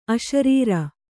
♪ aśarīra